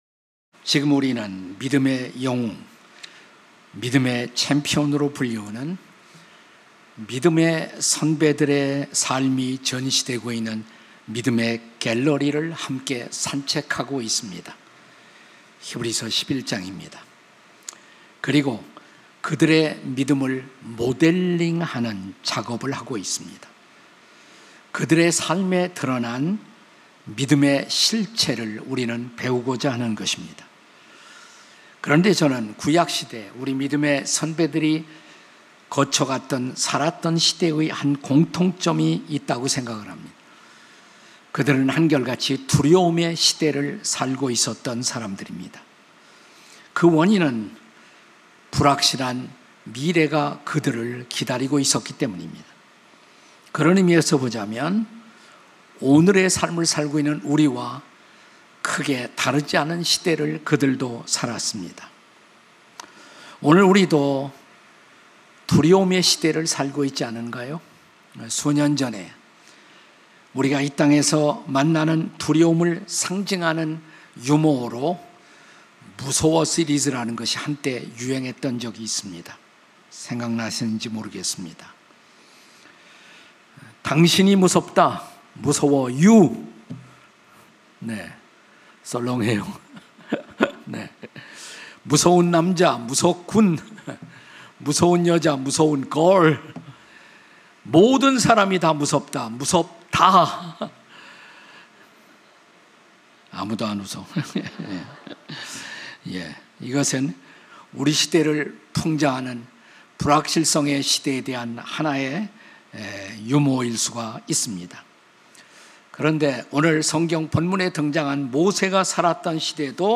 설교 : 주일예배 히브리서 - (17) 믿음의 모델링에 도전하라 4.